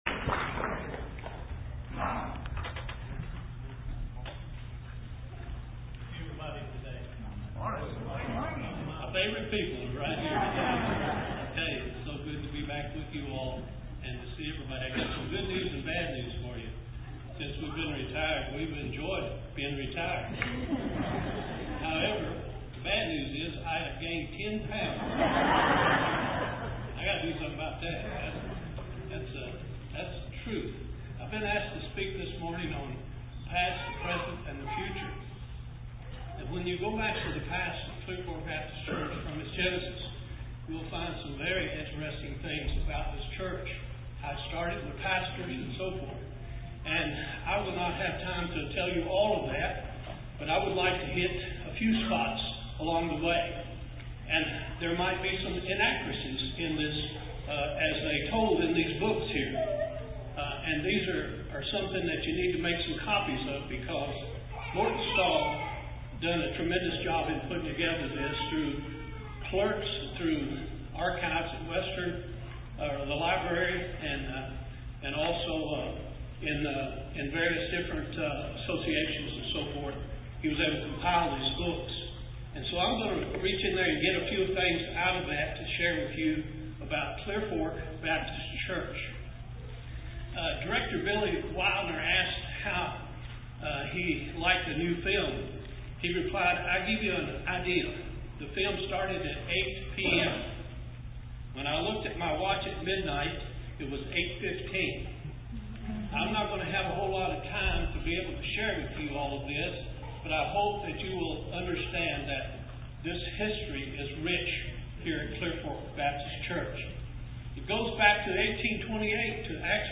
Sermon-4-9-17.mp3